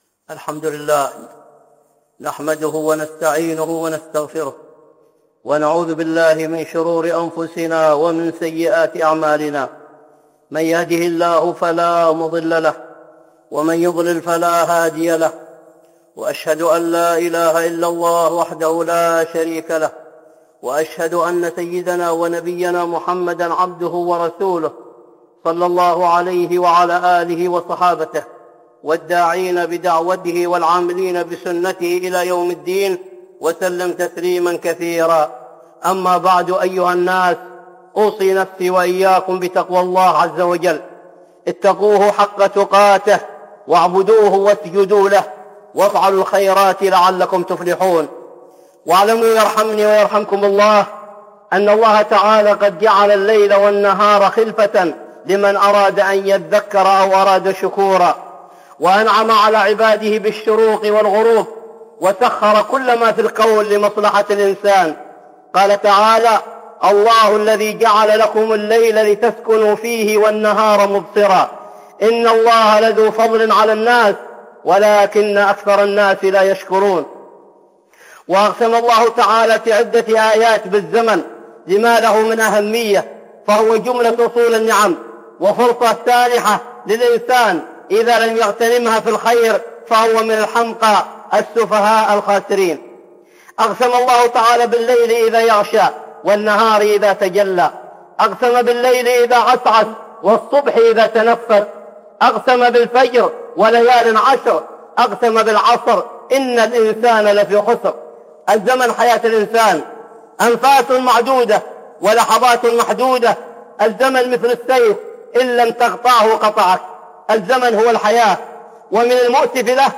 (خطبة جمعة) إغتنام الوقت ومحاسبة النفس